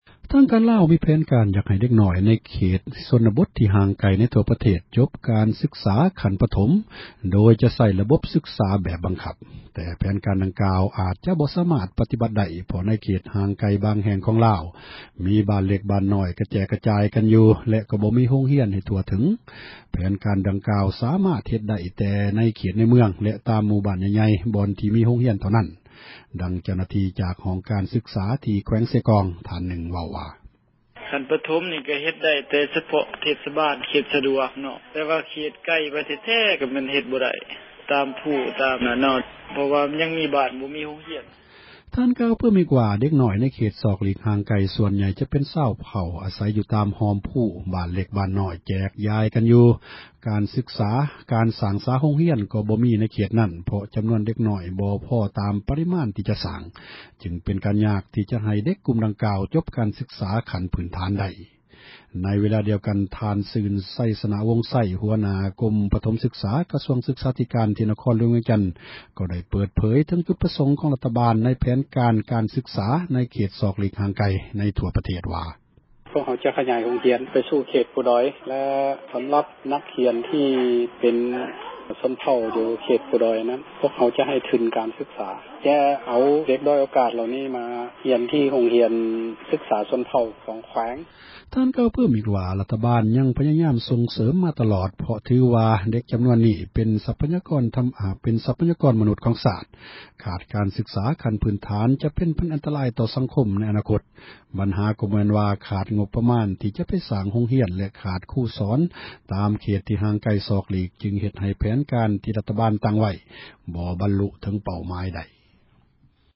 ຢາກໃຫ້ ເດັກນ້ອຍ ຊົນນະບົດ ສອກຫລີກ ຈົບ ຂັ້ນ ສືກສາ ພື້ນຖານ — ຂ່າວລາວ ວິທຍຸເອເຊັຽເສຣີ ພາສາລາວ